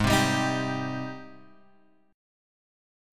G#M7 chord {4 3 6 5 x 3} chord